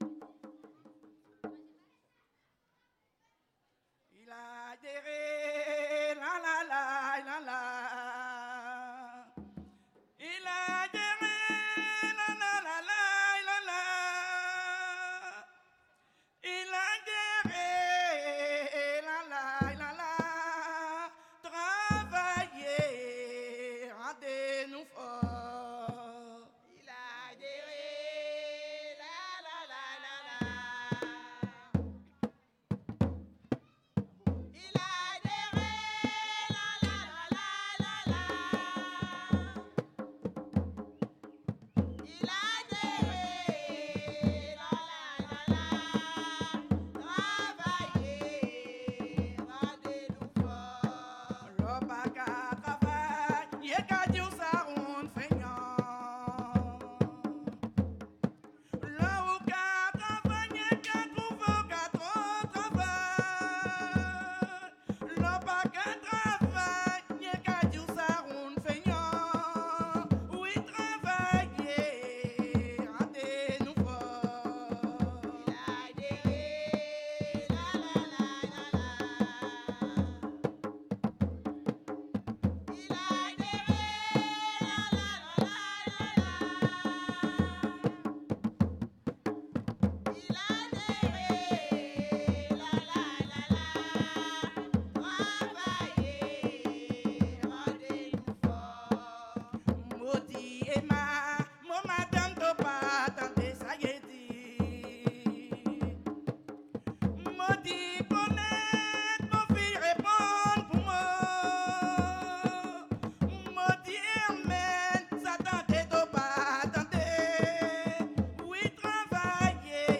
danse : grajé (créole)
Pièce musicale inédite